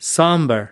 /əˈpriː.ʃi.eɪt/